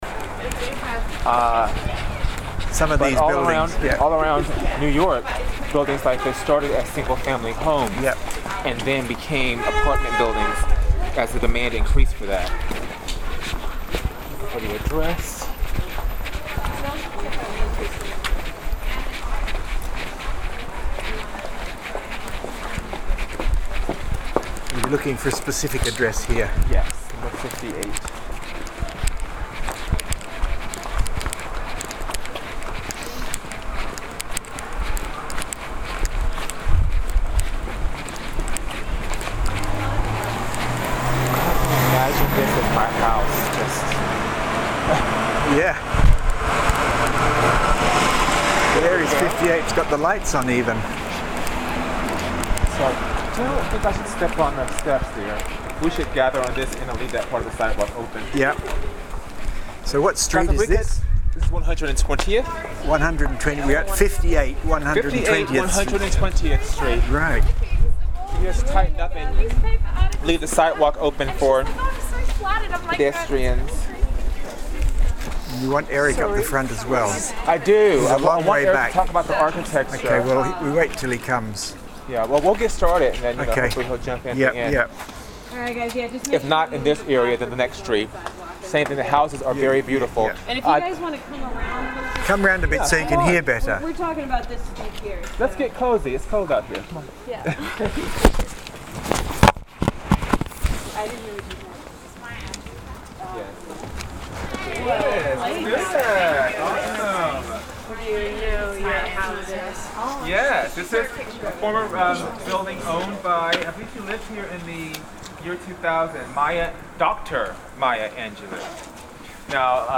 our fabulous guide